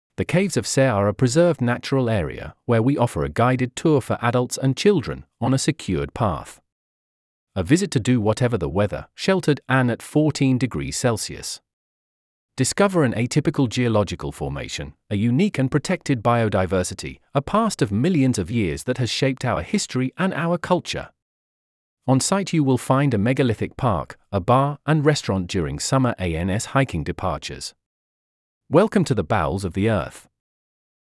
Audio description available
presentation_gs2023_yt_audiodescription_en.mp3